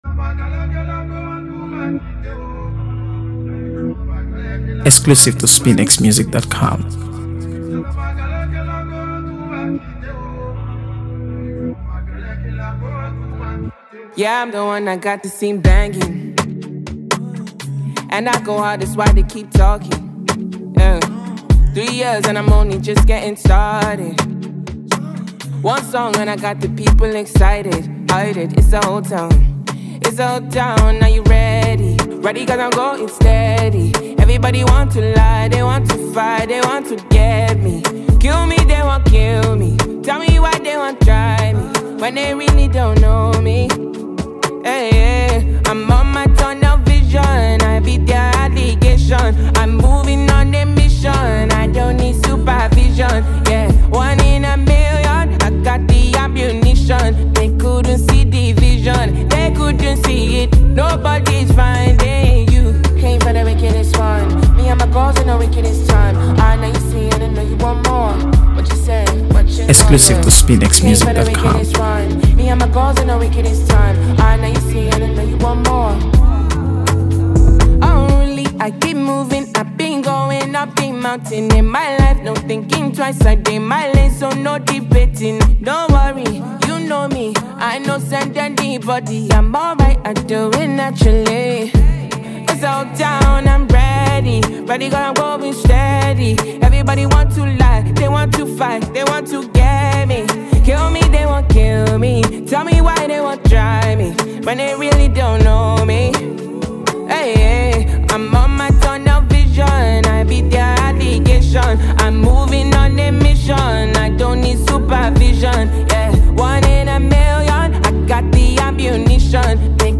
AfroBeats | AfroBeats songs
Grammy-winning Nigerian singer and songwriter
” brimming with energy and emotion.